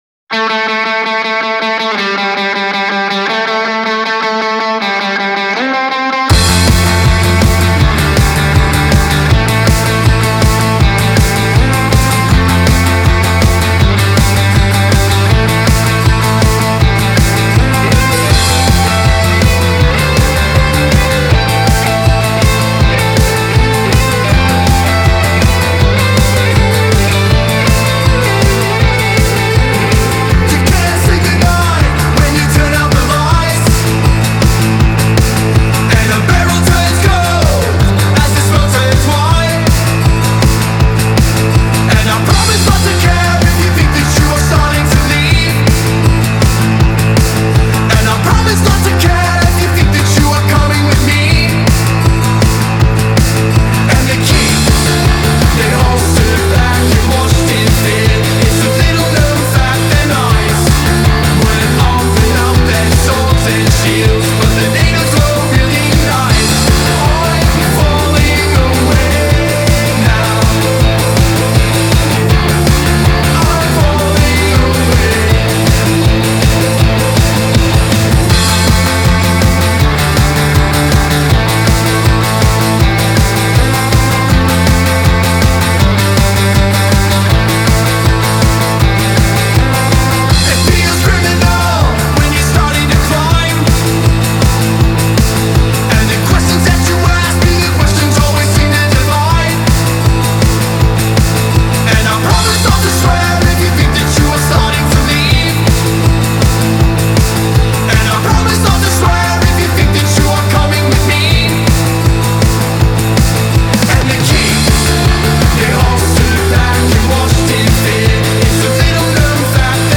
Категория: Рок музыка
зарубежный рок